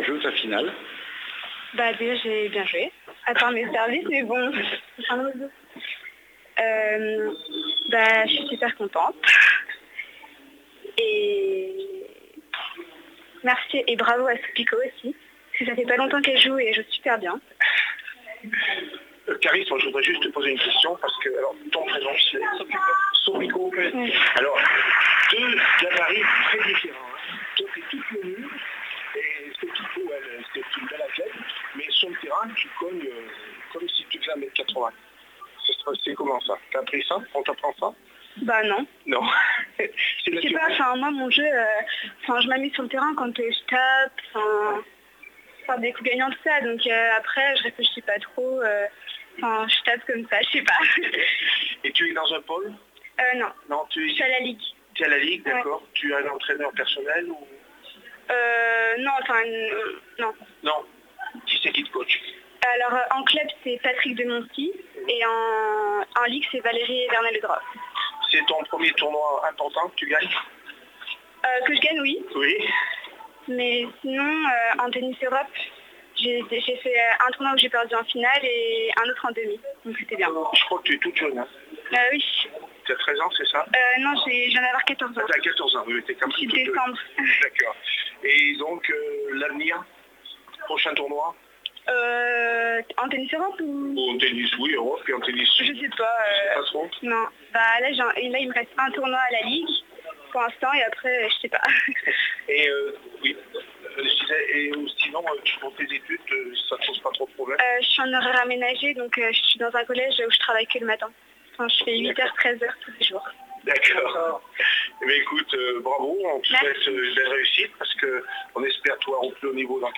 REACTIONS DES FINALISTES